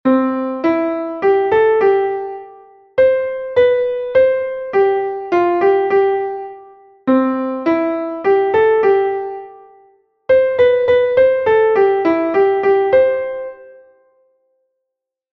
Entoación a capella
Melodía en 3/4 en Do M